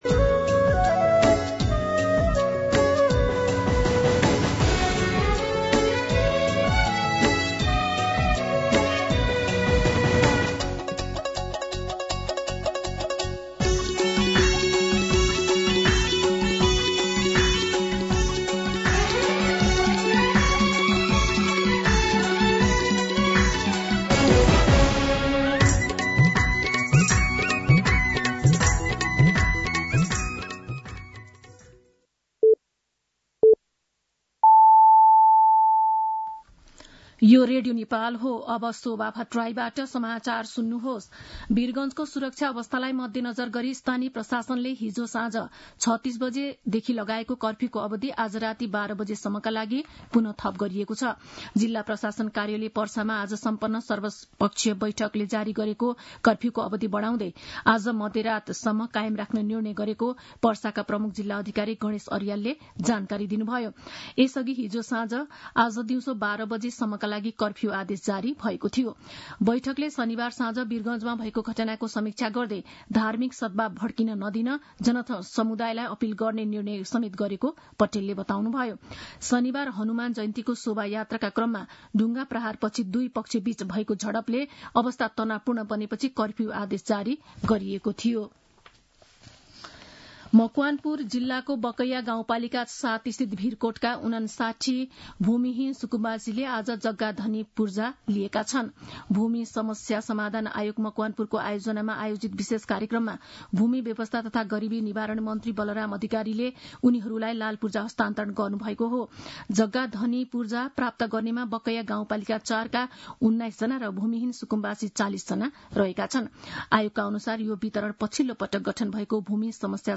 मध्यान्ह १२ बजेको नेपाली समाचार : ३१ चैत , २०८१
12-pm-Nepali-News-4.mp3